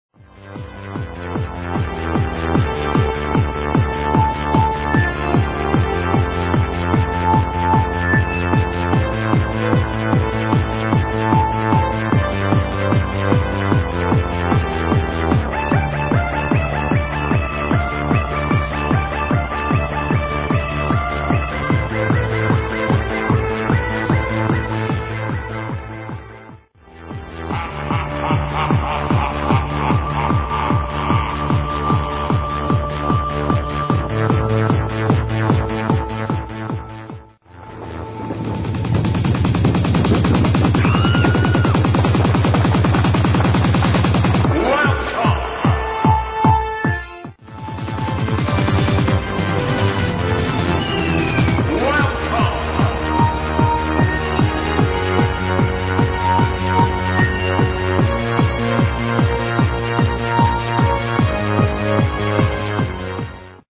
This it's hard!! i recorded it from radio,
One of the first trance-progressive tracks, i guess